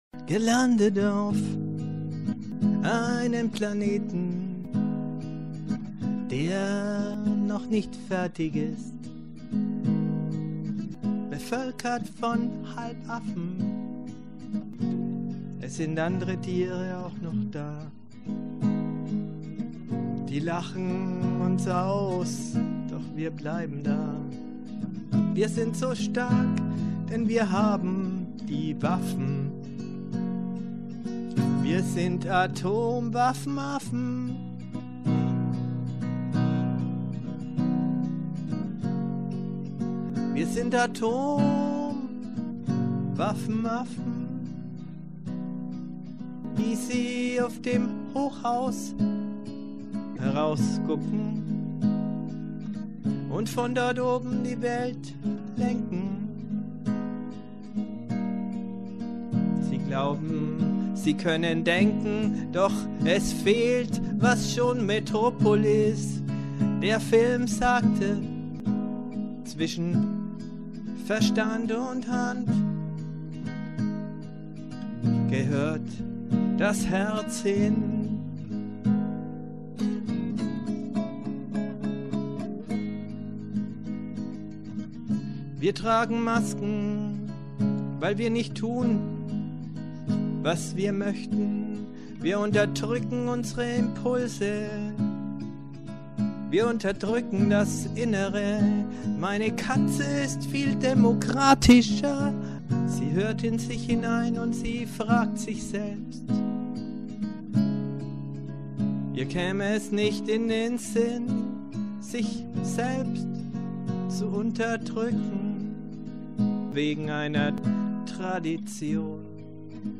Gesang, Gitarre